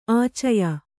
♪ ācaya